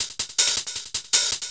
Hats 07.wav